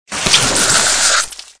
AA_squirt_neonwatergun_miss.ogg